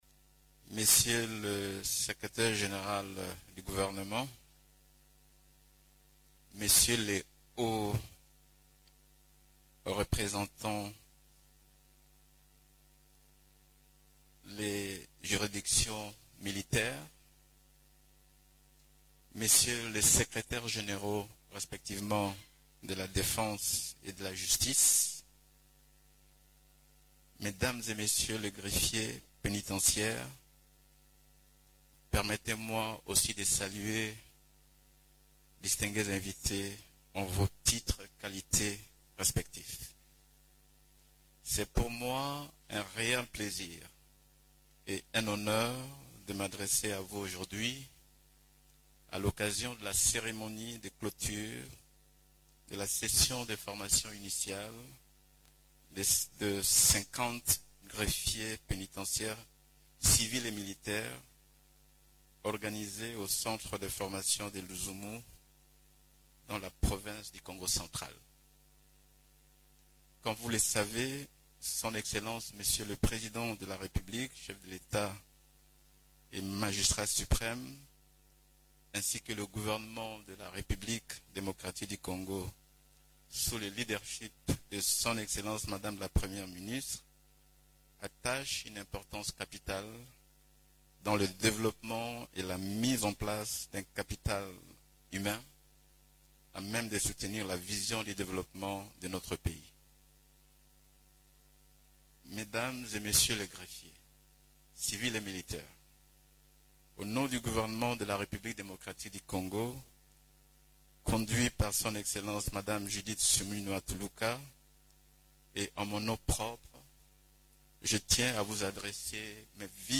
Quarante-deux greffiers pénitentiaires, civils et militaires, dont 16 femmes, ont reçu leurs brevets, jeudi 21 aout, au cours d’une cérémonie, marquant la fin de la 5e session de formation intensive de trois mois, dispensée par l’Institut national de formation judiciaire (INAFORJ).
Suivez les propos de Guillaume Ngefa Atondoko: